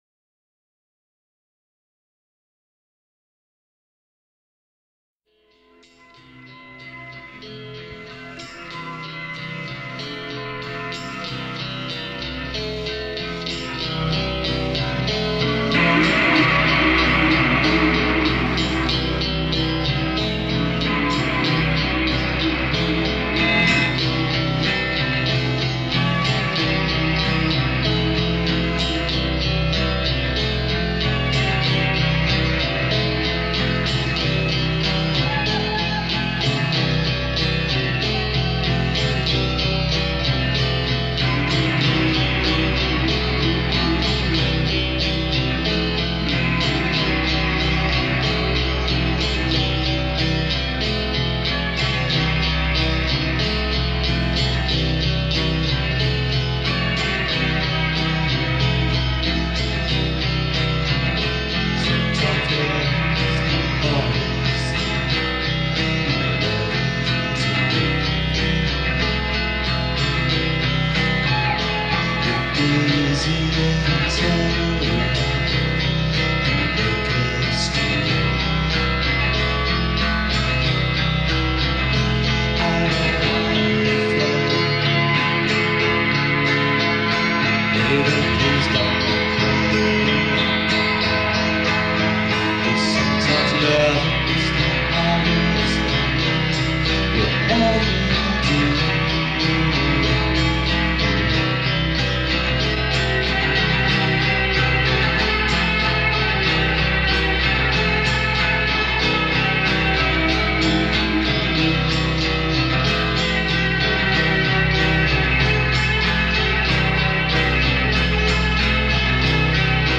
Shoegaze